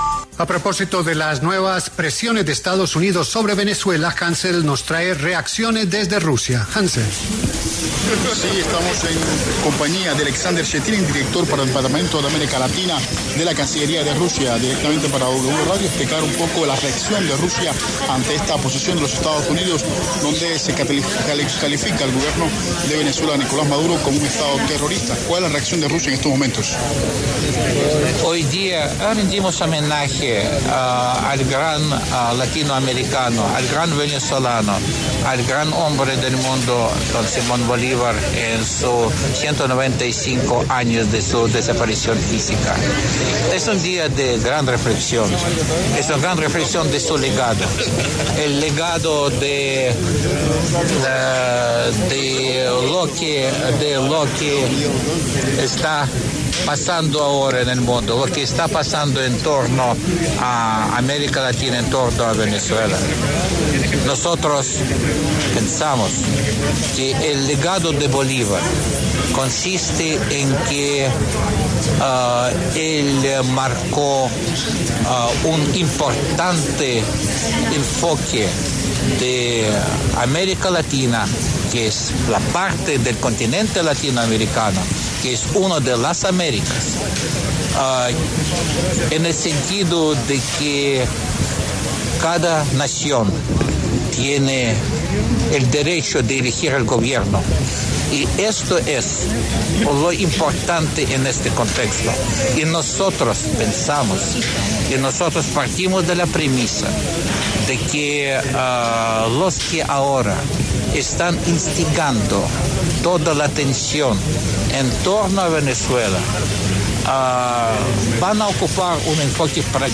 Alexander Shetinin, director para el Parlamento de América Latina de la Cancillería de Rusia, pasó por los micrófonos de La W para hablar sobre la posición de los Estados Unidos al calificar al Gobierno de Venezuela como un estado terrorista.